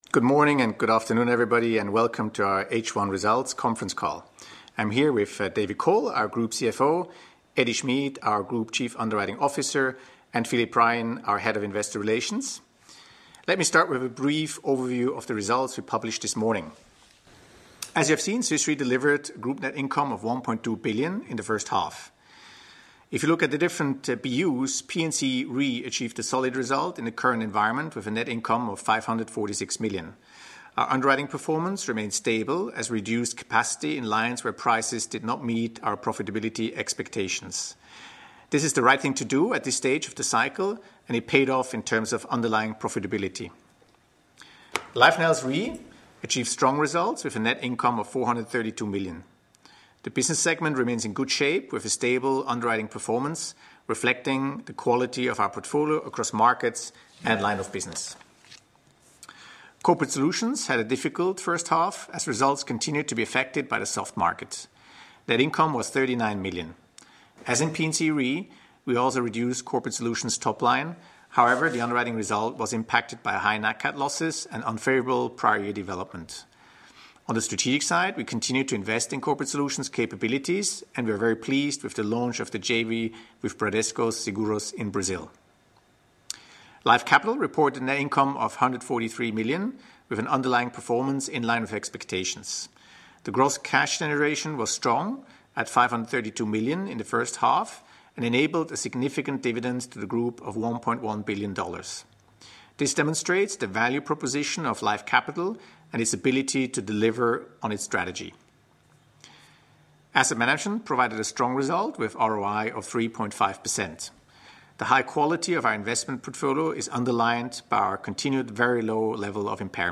Analysts Conference call recording
2017_hy_qa_audio.mp3